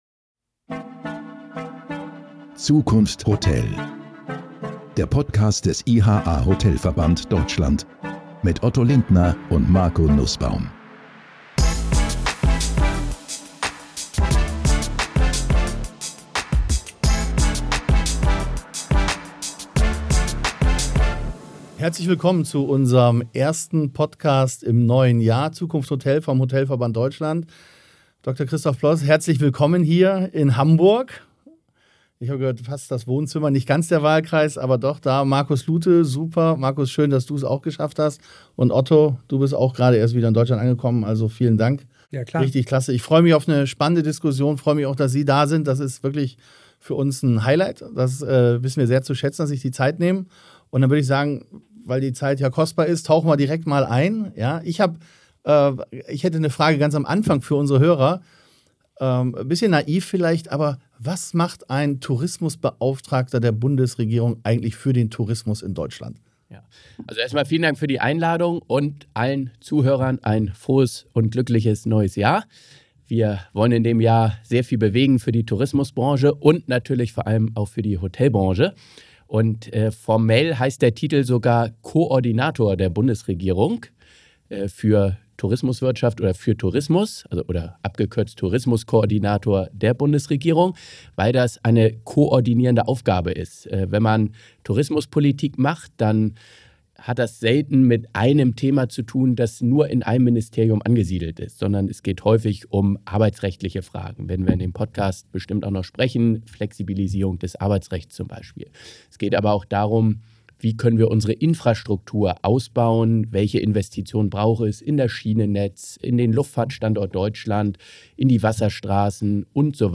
Dr. Christoph Ploß, Koordinator der Bundesregierung für maritime Wirtschaft und Tourismus, erläutert im Gespräch mit dem Hotelverband Deutschland (IHA), wie die neue Nationale Tourismusstrategie Wettbewerbsfähigkeit, Investitionen und Beschäftigung auch in Hotellerie und Gastronomie stärken soll.